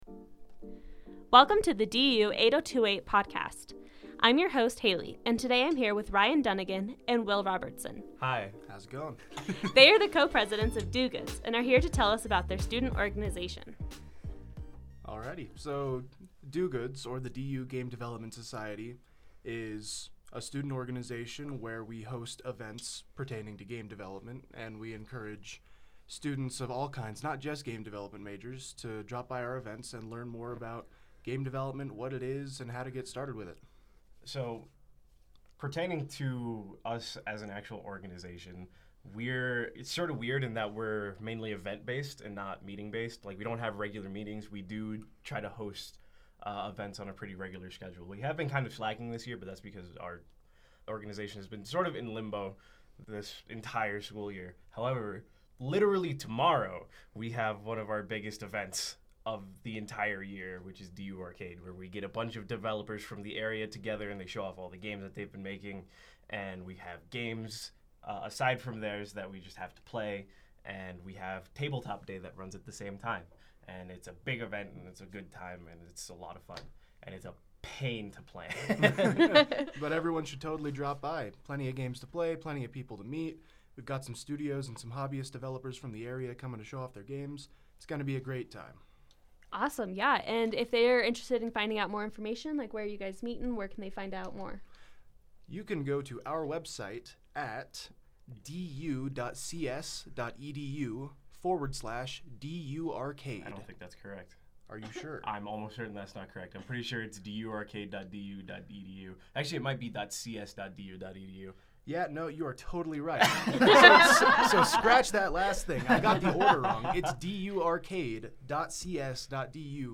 The DU Clarion Background music